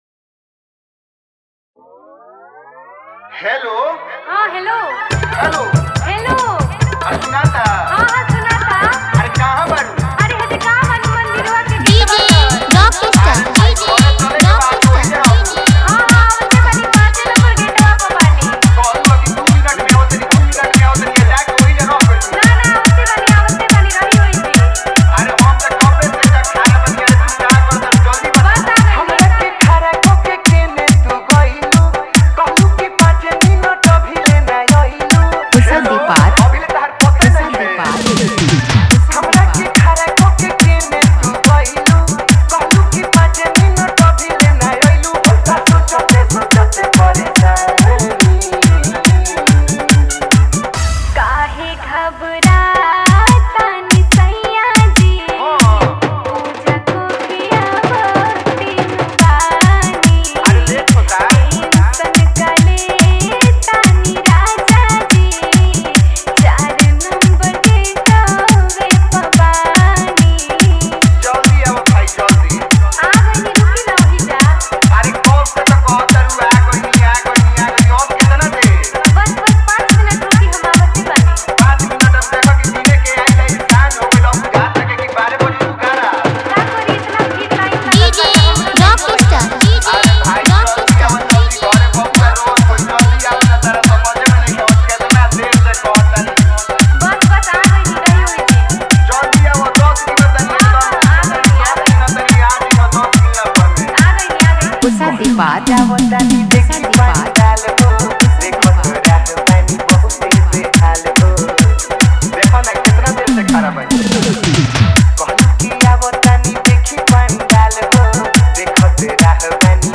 Category:  2021 Dj Remix Songs